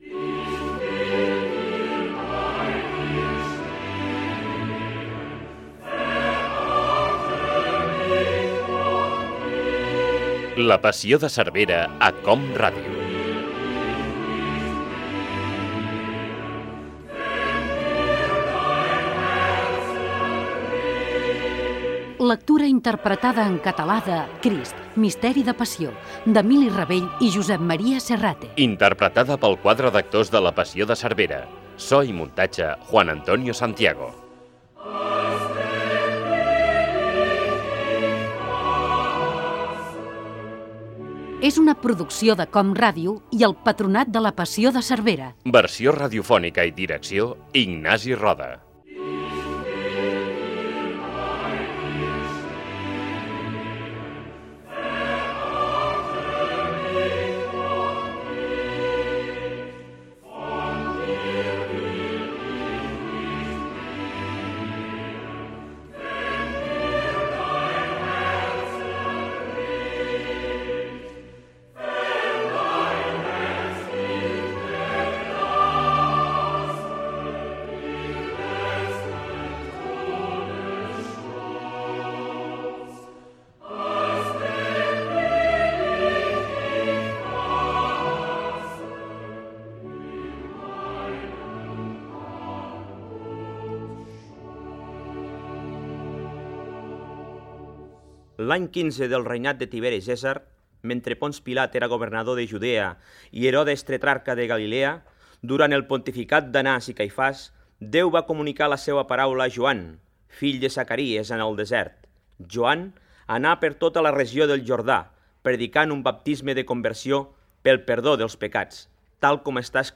Lectura interpretada pel quadre d'actors de La Passió de Cervera.
Ficció